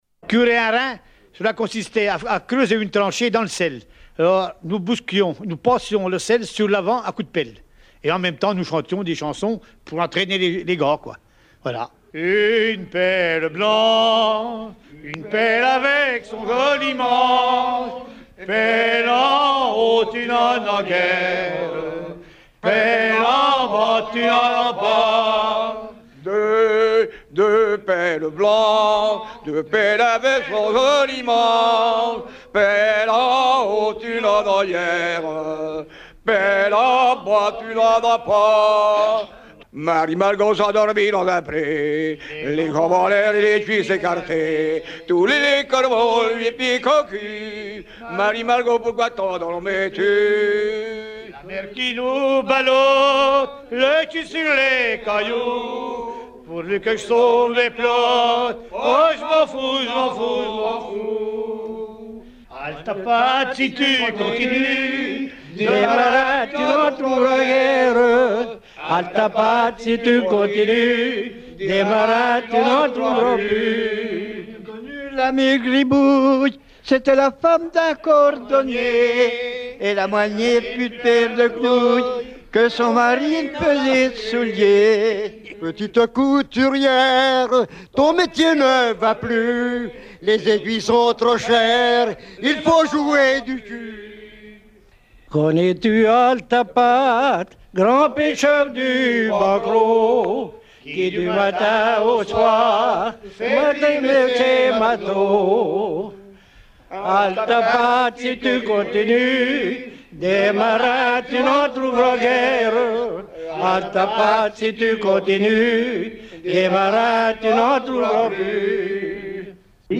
Collectif de terre-neuvas de Plouer-Sur-Rance
Enregistrement réalisé en 1978 auprès d'anciens terre-neuvas
circonstance : maritimes